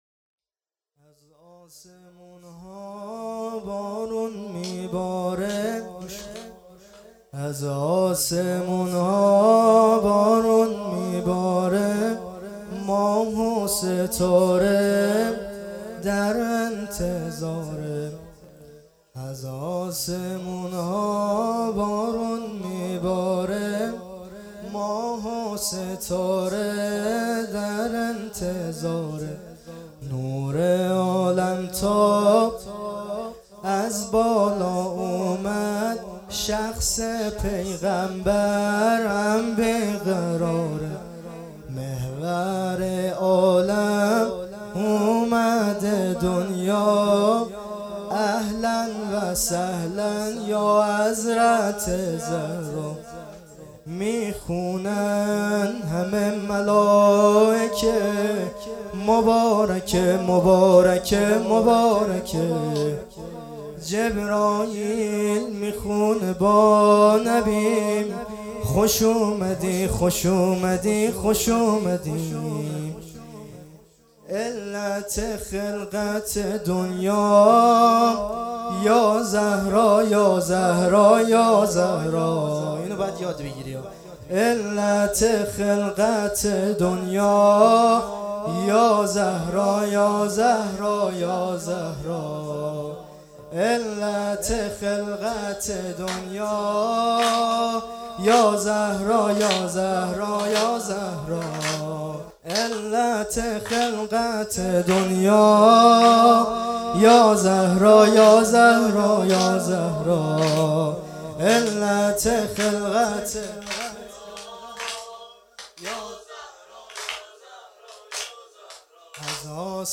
جشن ولادت حضرت زهرا سلام الله ۱۶-۱۱-۹۹